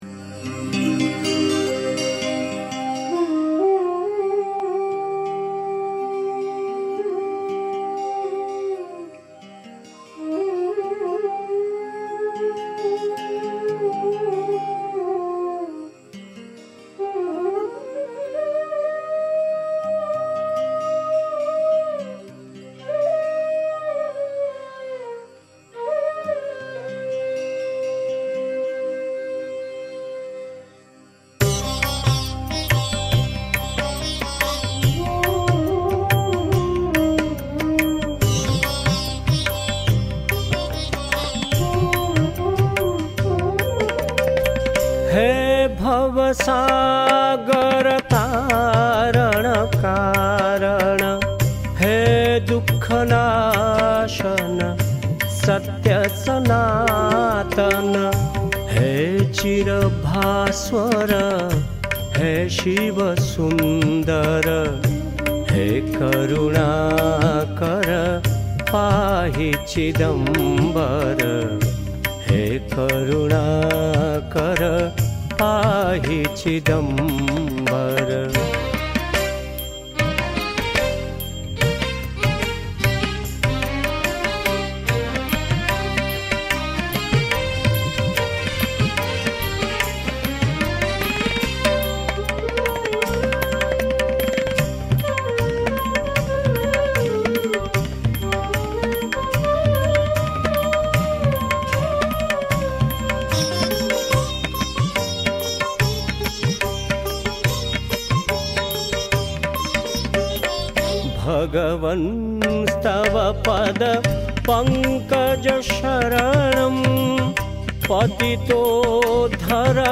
On this important day, we are presenting the musical offering ‘He Bhavasagara.’